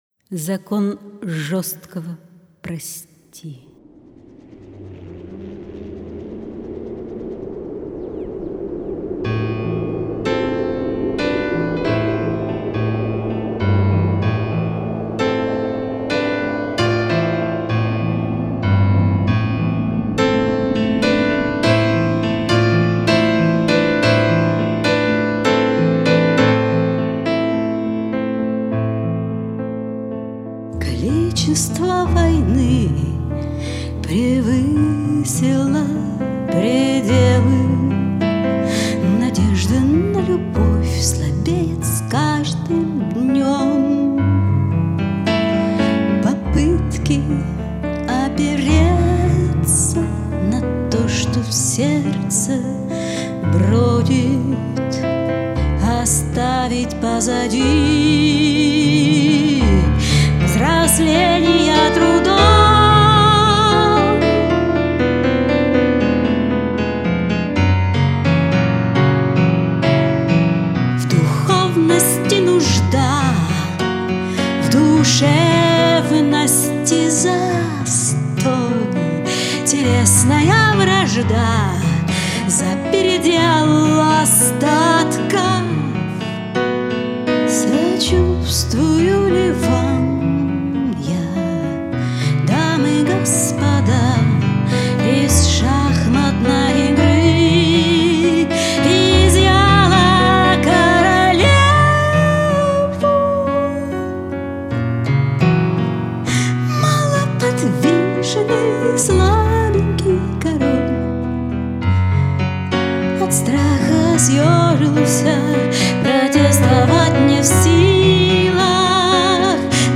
Приятный голос )))